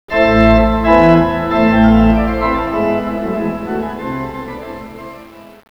organ.wav